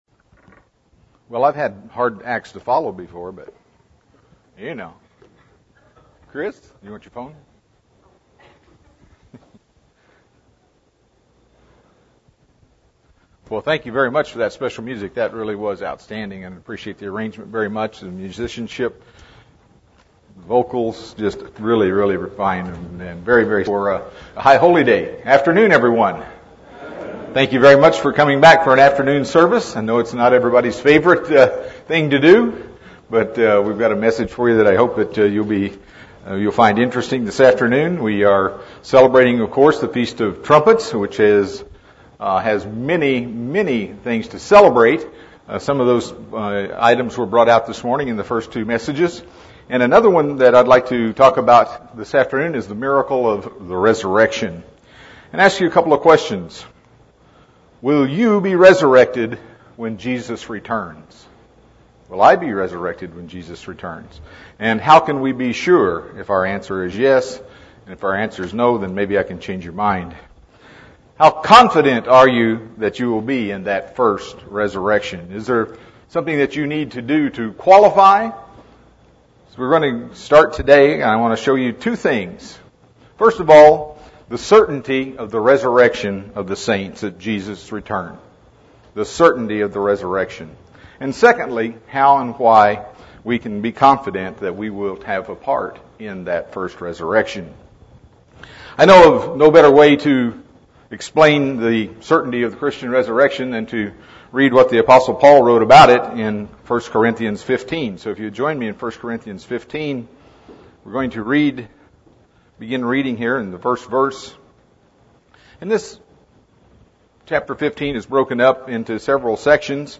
Given in San Diego, CA
UCG Sermon Studying the bible?